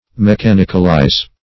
Mechanicalize \Me*chan"ic*al*ize\, v. t. To cause to become mechanical.